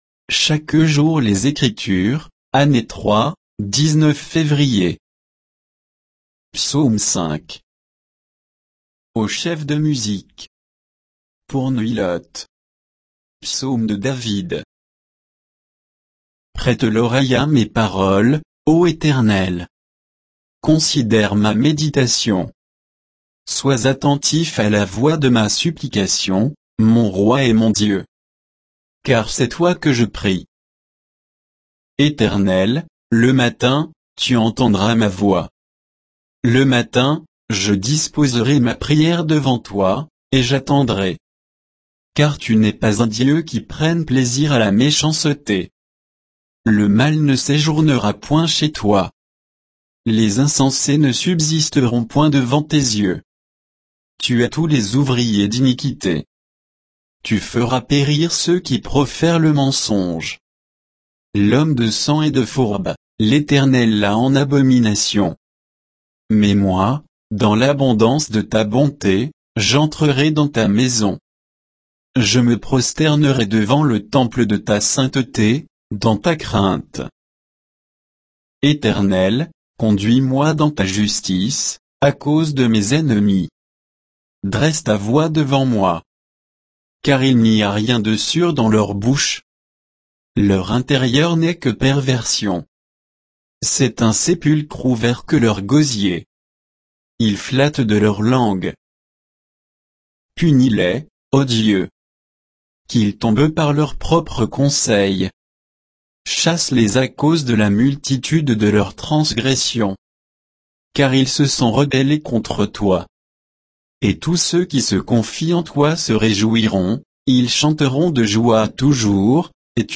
Méditation quoditienne de Chaque jour les Écritures sur Psaume 5